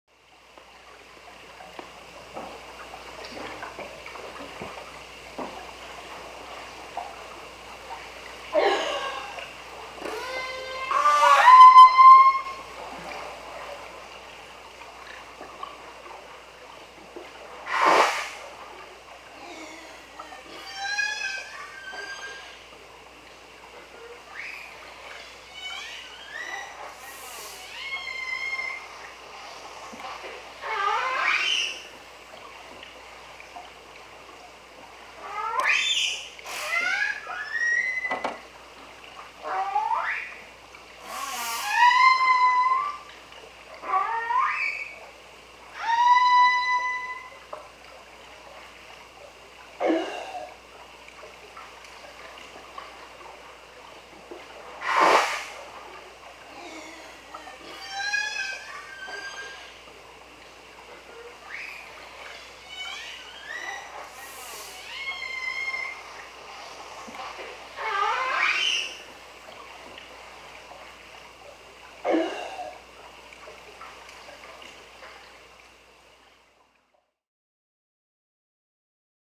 دانلود آهنگ وال 10 از افکت صوتی انسان و موجودات زنده
دانلود صدای وال 10 از ساعد نیوز با لینک مستقیم و کیفیت بالا
برچسب: دانلود آهنگ های افکت صوتی انسان و موجودات زنده دانلود آلبوم صدای حیوانات آبی از افکت صوتی انسان و موجودات زنده